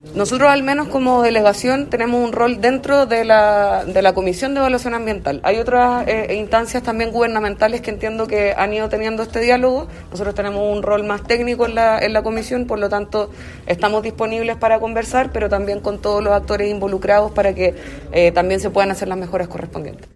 La delegada presidencial de la Región Metropolitana, Constanza Martínez, conversó con La Radio y se refirió a su decisión de rechazar el proyecto “Egaña Comunidad Sustentable”.